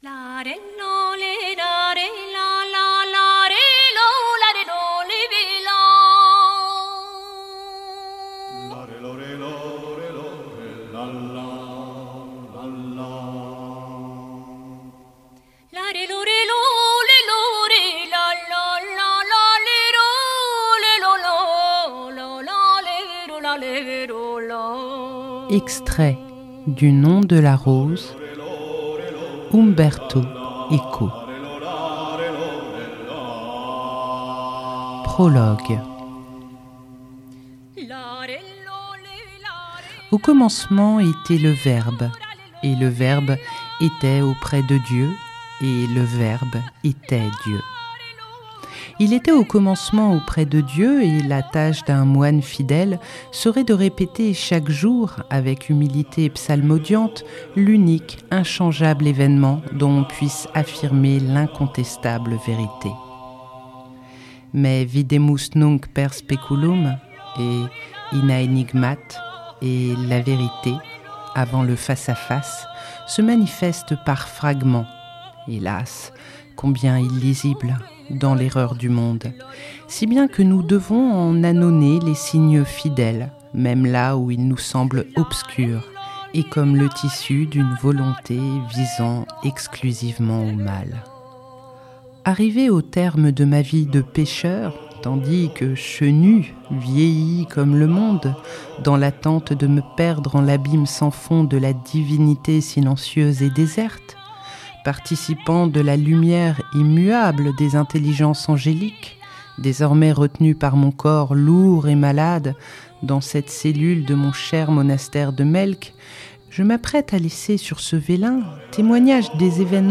Extraits du « Nom de la rose » (40:40)